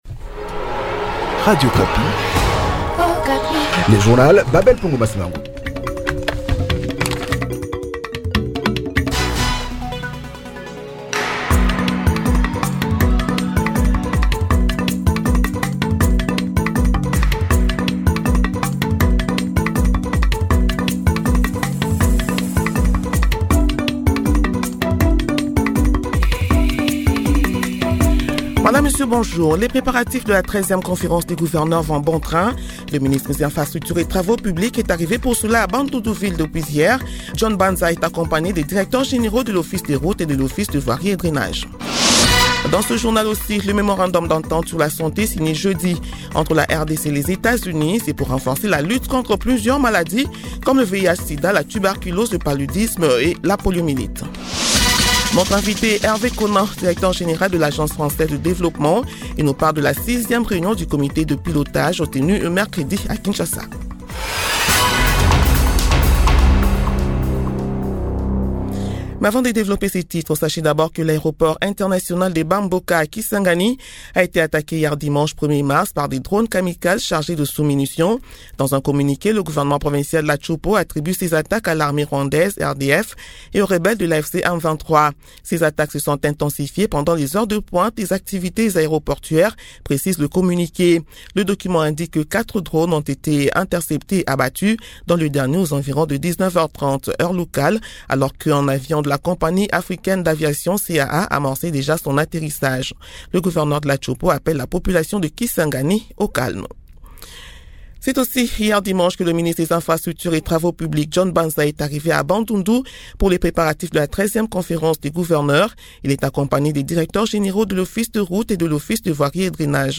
Journal matin 6 heures